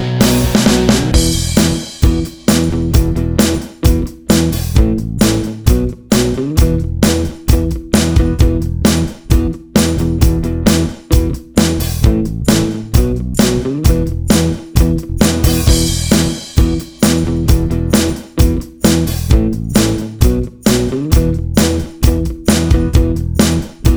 No Harmony Pop (1980s) 3:34 Buy £1.50